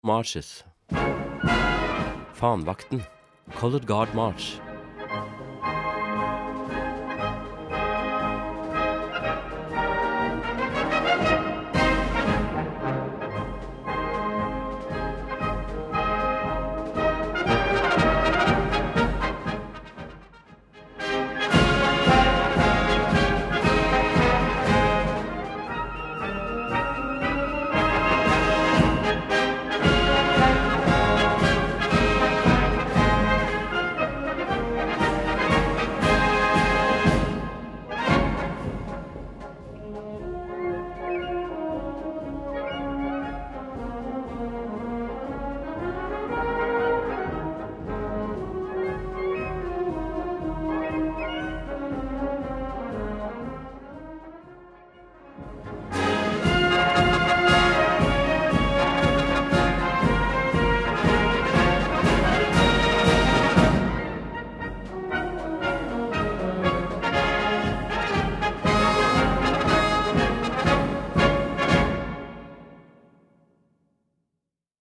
2:25 Minuten Besetzung: Blasorchester Tonprobe